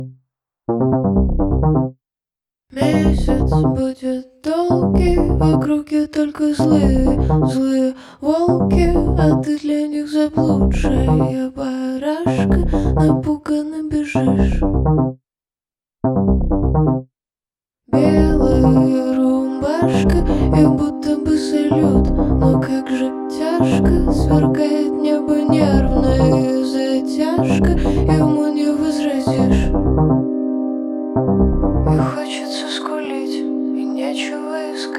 Жанр: Иностранный рок / Рок / Инди / Русские
# Indie Rock